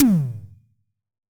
Tom_B1.wav